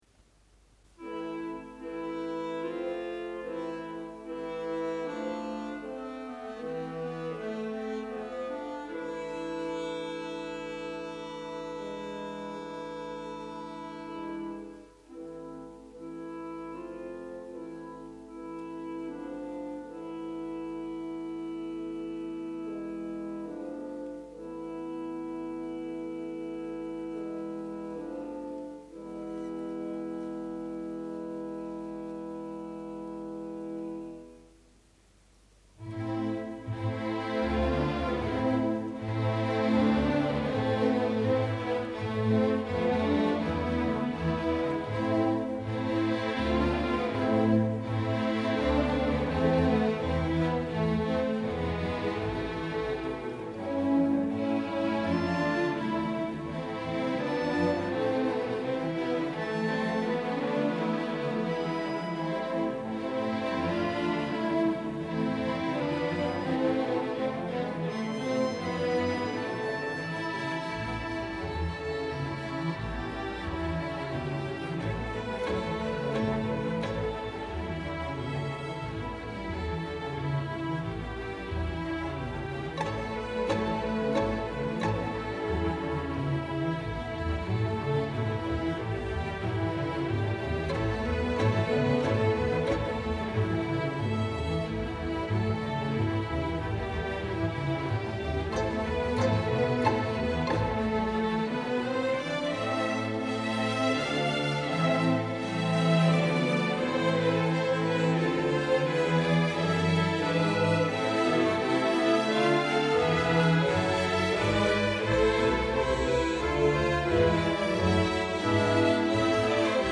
L’orchestration de cette oeuvre en cinq mouvements -les deux derniers sont enchaînes- fait appel, outre l’orchestre classique traditionnel, à une soliste soprano qui chante sans paroles, à un modeste chœur de femmes à trois voix et à un large instrumentarium avec orgue, piano machine à vent et un panel varié de percussions.